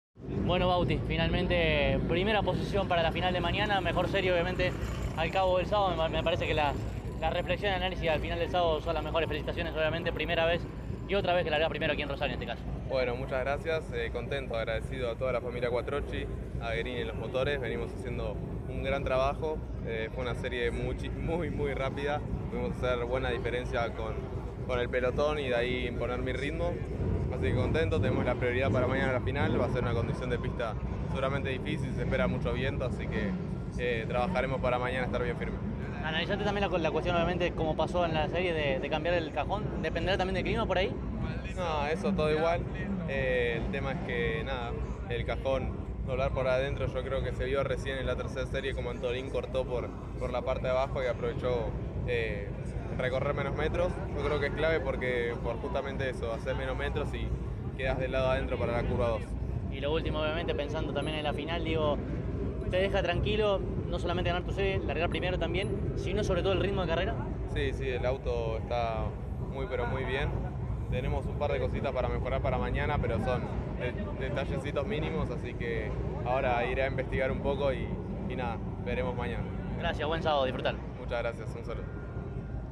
en diálogo exclusivo con CÓRDOBA COMPETICIÓN